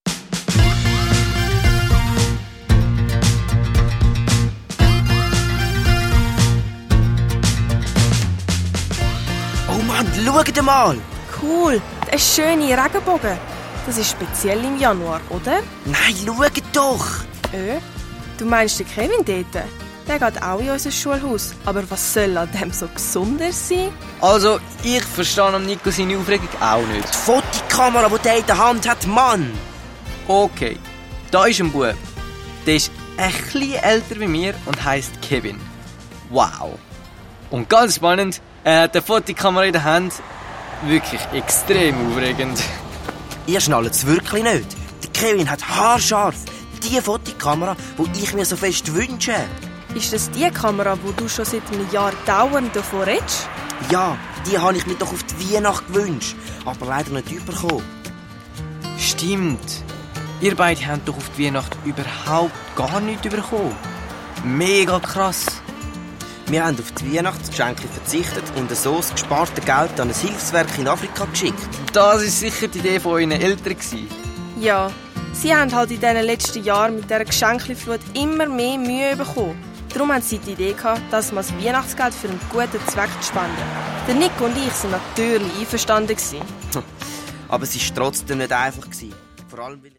Hörspiel-CD mit Download-Code